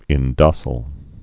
(ĭn-dŏsəl)